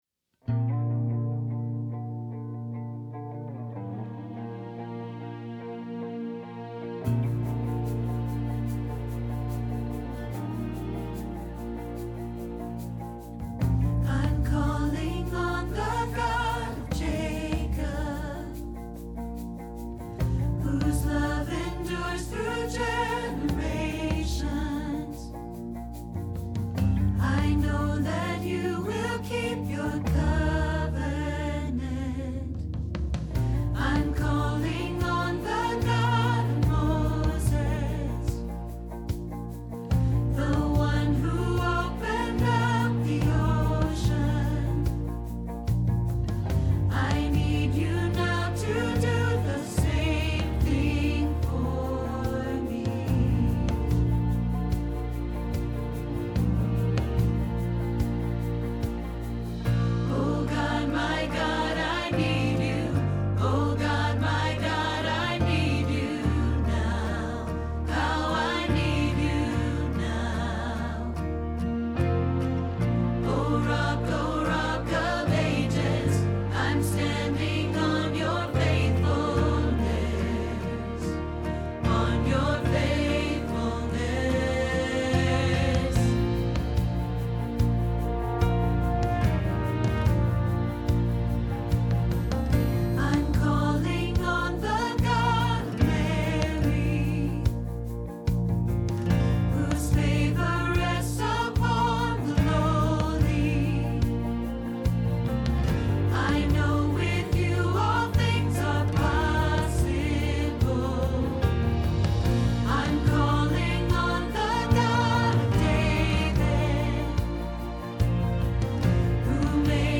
Alto
Hilltop Choir